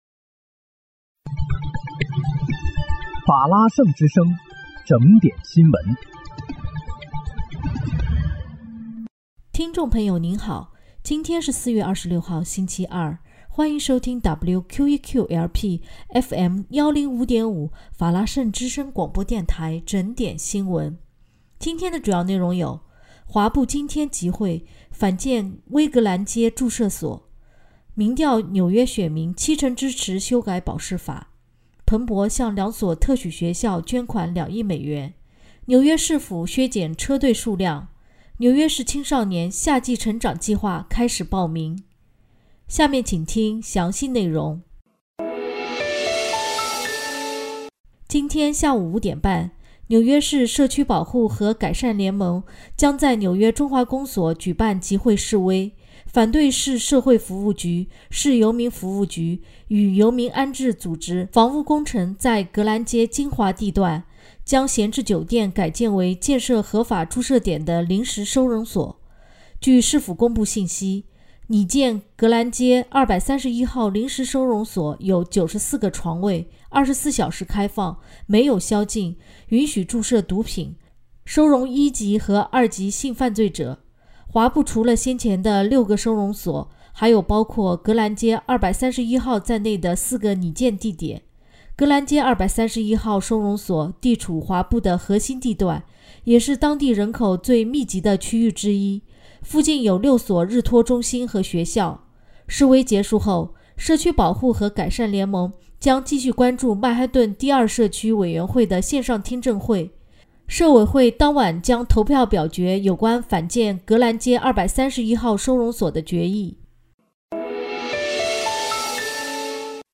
4月26日（星期二）纽约整点新闻
听众朋友您好！今天是4月26号，星期二，欢迎收听WQEQ-LP FM105.5法拉盛之声广播电台整点新闻。